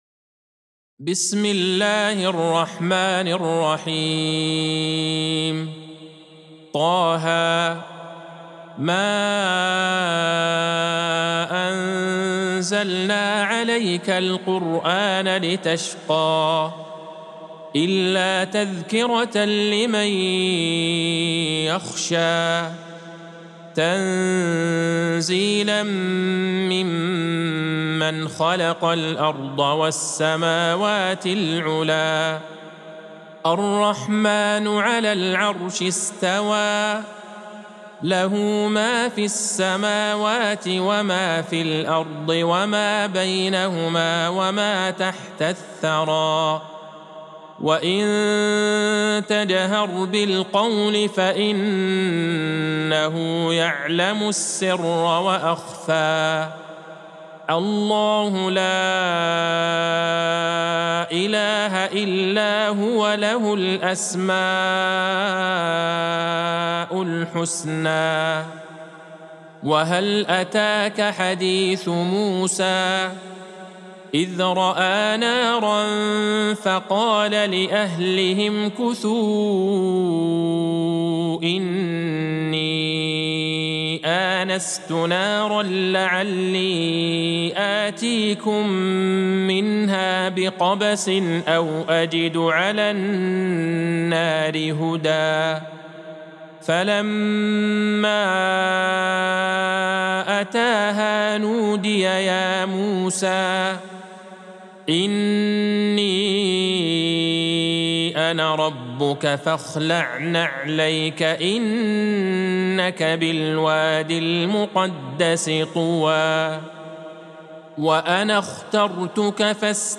سورة طه Surat Ta-Ha | مصحف المقارئ القرآنية > الختمة المرتلة